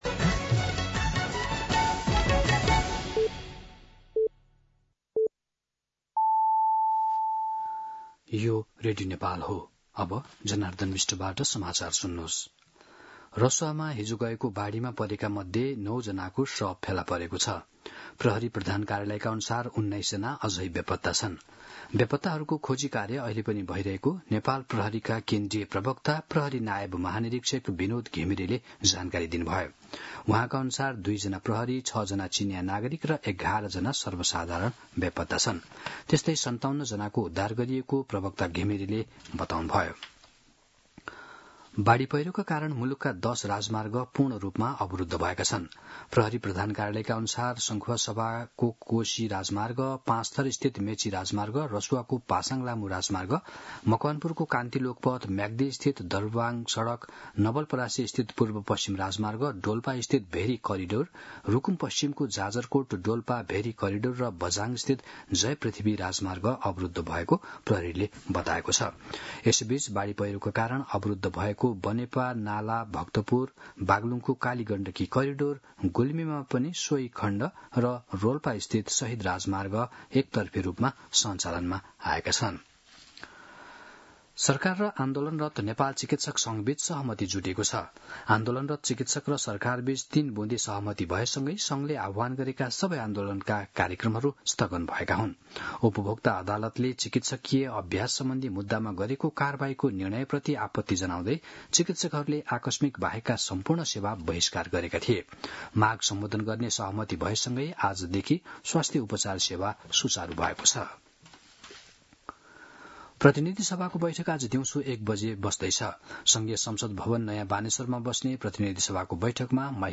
मध्यान्ह १२ बजेको नेपाली समाचार : २५ असार , २०८२